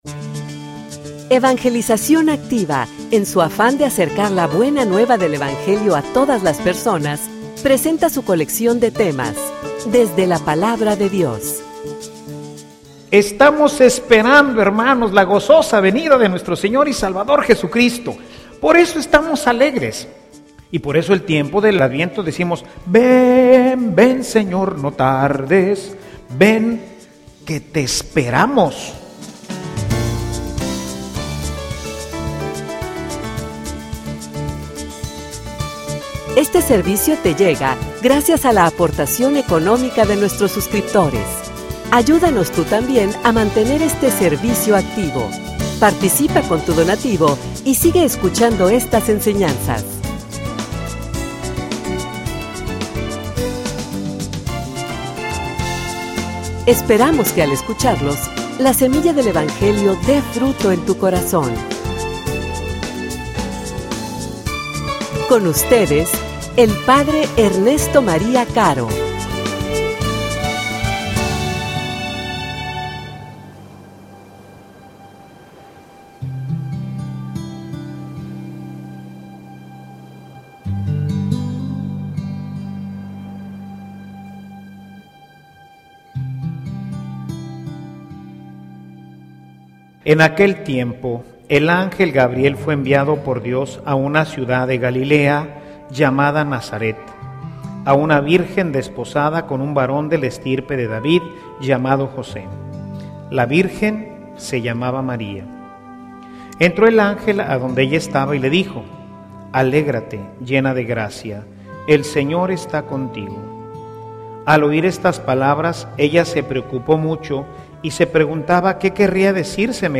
homilia_Ven_Senor_Jesus.mp3